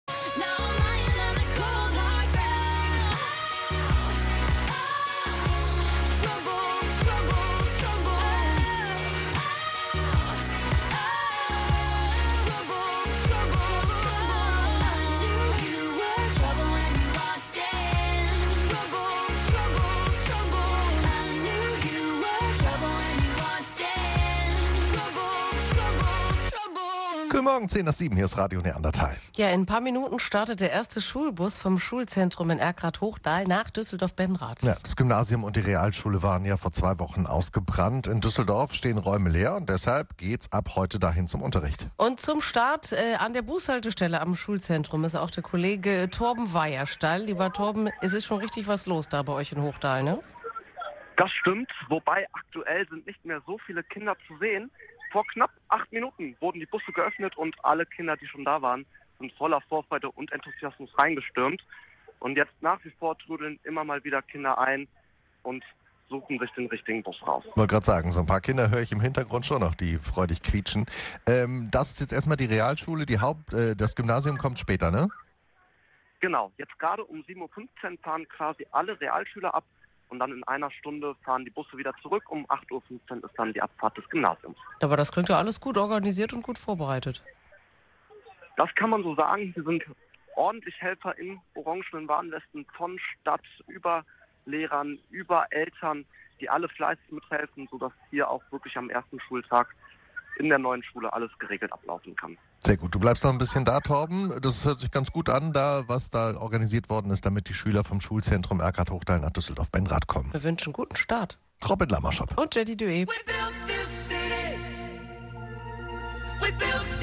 Wir waren heute live für Euch dabei!